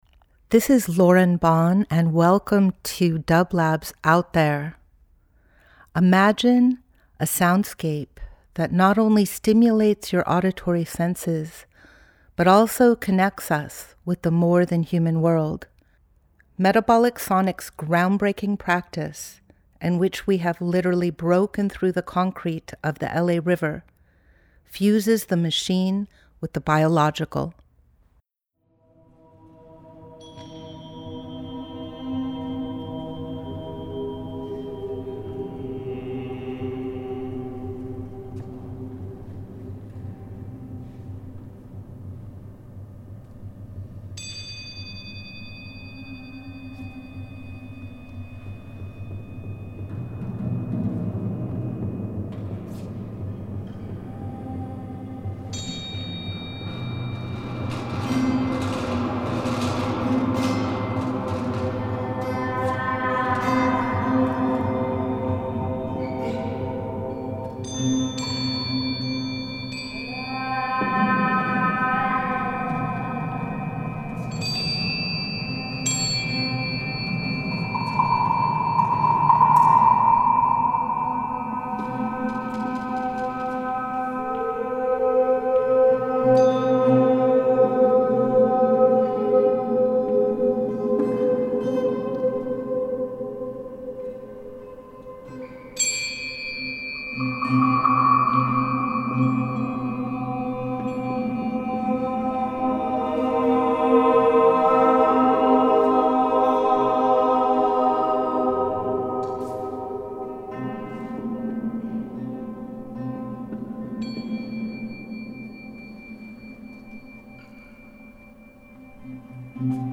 Ambient Experimental Field Recording Future Roots